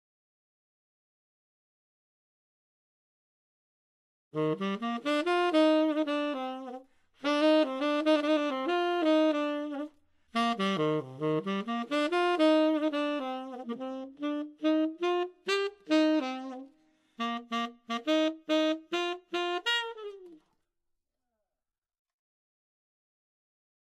Jazz-Sax.mp3